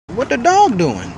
LBpvRx765WU_what-the-dog-doing-vine-sound-effect.mp3